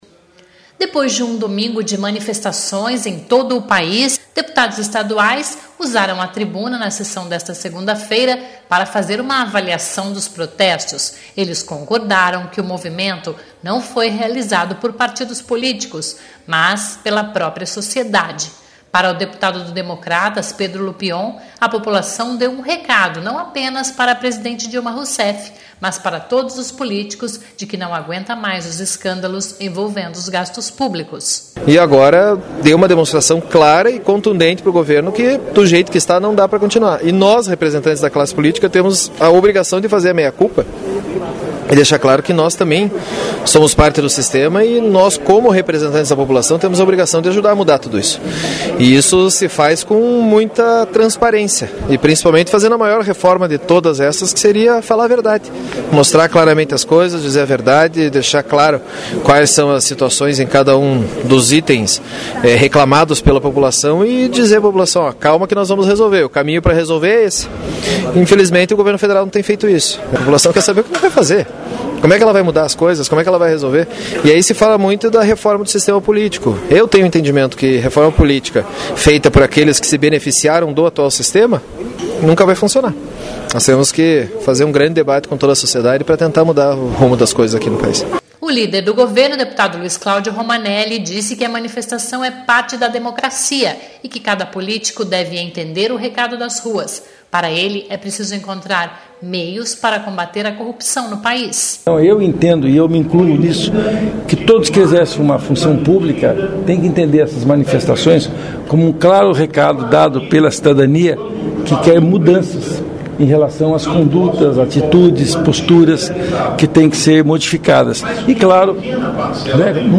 Depois de um domingo de manifestações em todo o país, deputados estaduais usaram a tribuna na sessão desta segunda-feira, para fazer uma avaliação dos protestos. Eles concordaram que o movimento não foi realizado por partidos políticos, mas pela própria sociedade.